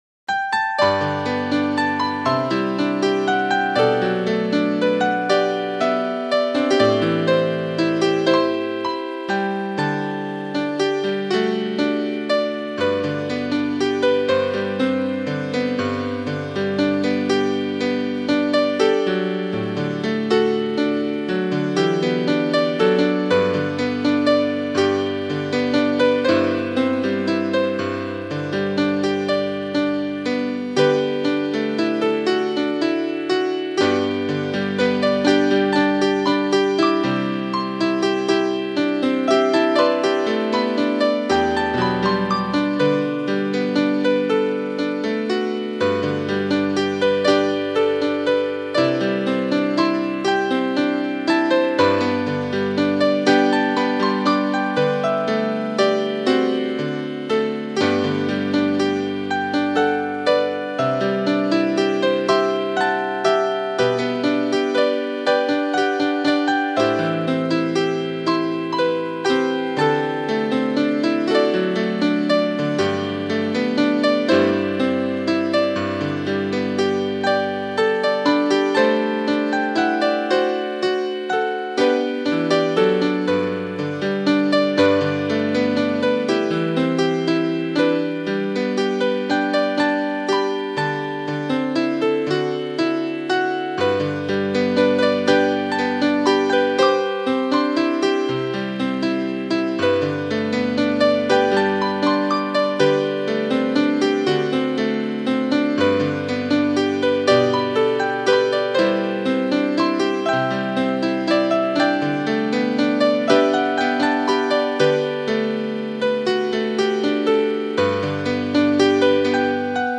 4 strofe Sol-M